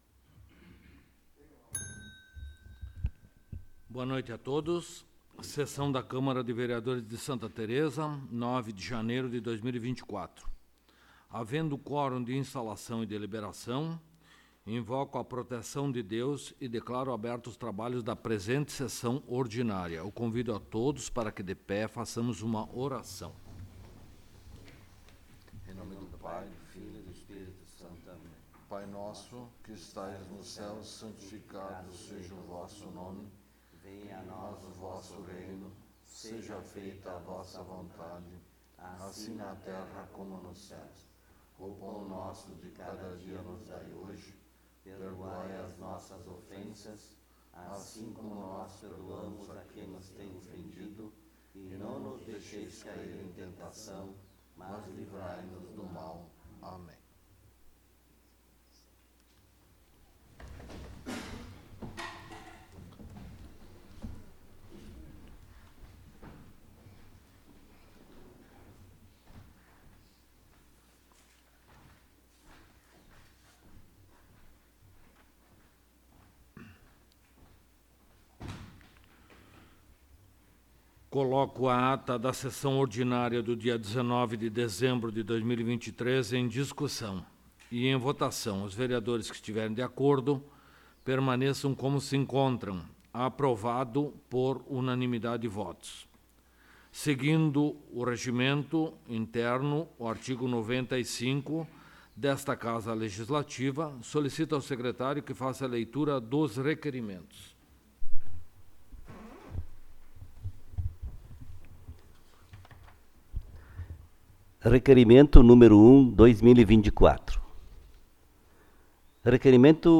1ª Sessão Ordinária de 2024
Local: Câmara Municipal de Vereadores de Santa Tereza